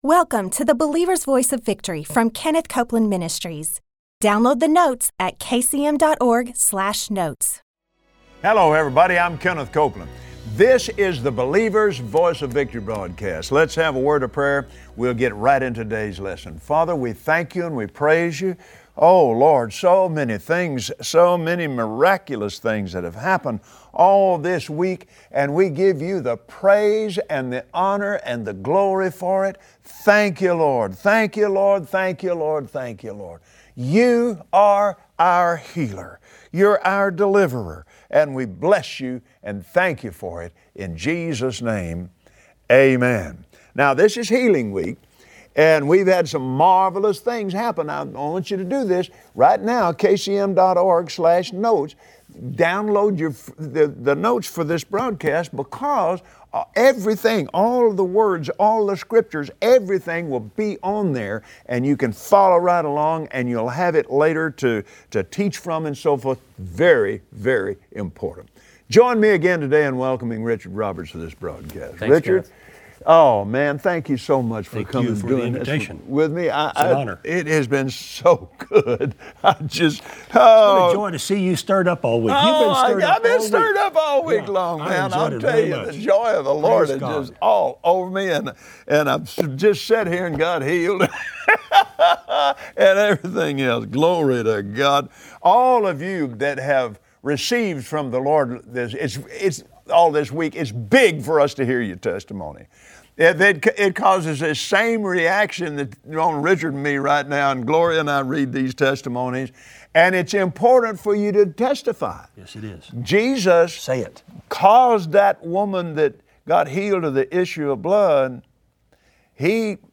Activate your faith through praise and thanksgiving to God. Kenneth Copeland and Richard Roberts teach us to believe and receive.